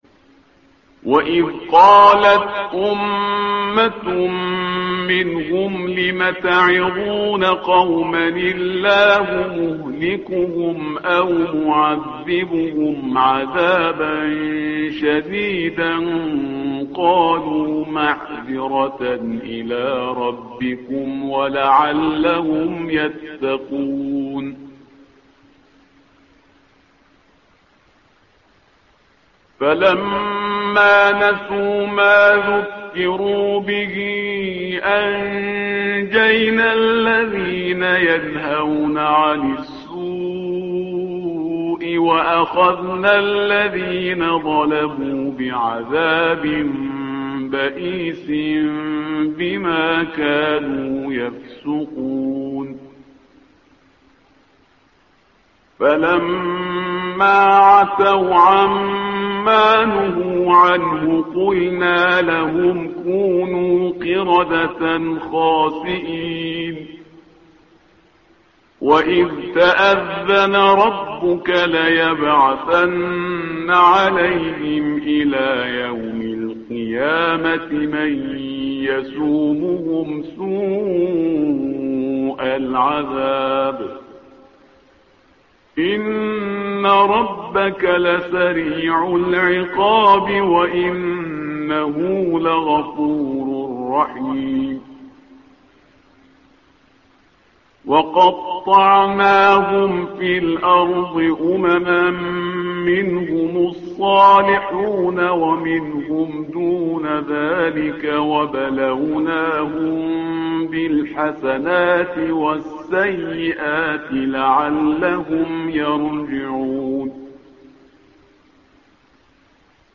تحميل : الصفحة رقم 172 / القارئ شهريار برهيزكار / القرآن الكريم / موقع يا حسين